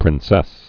(prĭn-sĕs)